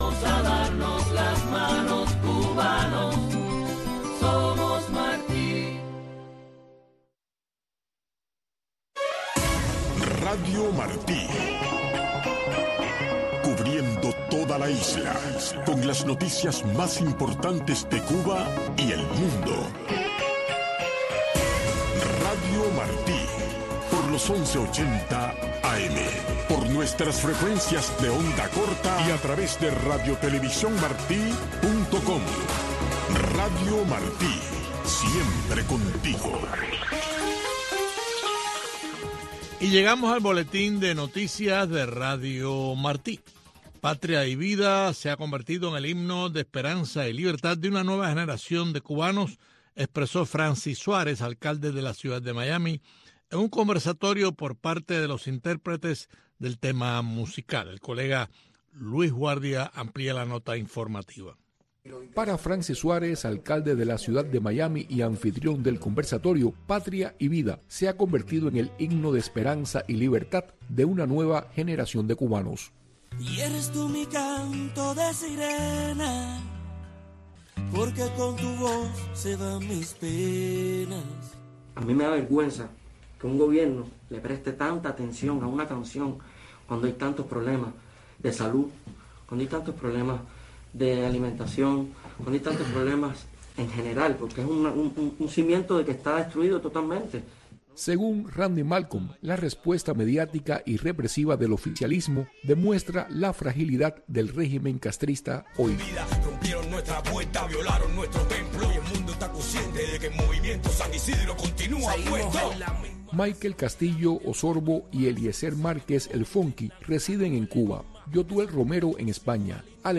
Un desfile de éxitos de la música pop internacional, un conteo regresivo con las diez canciones más importantes de la semana, un programa de una hora de duración, diseñado y producido a la medida de los jóvenes cubanos.